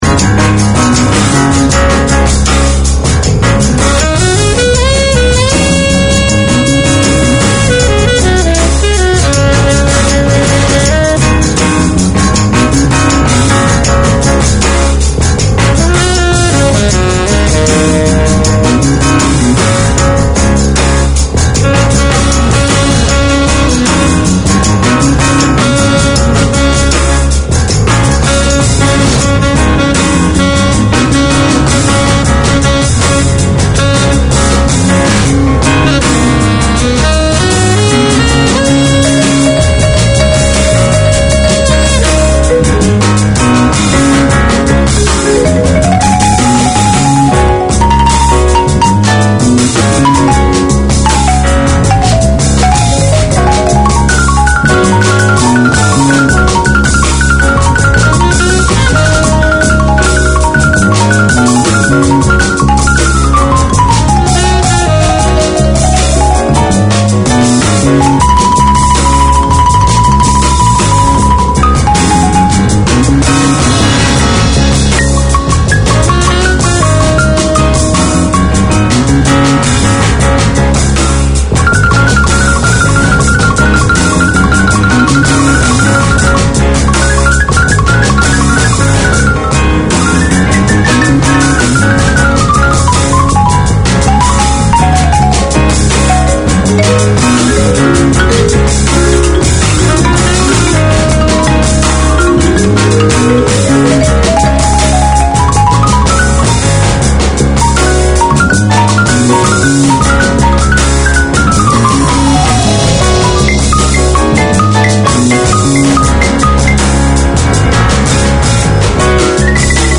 Community Access Radio in your language - available for download five minutes after broadcast.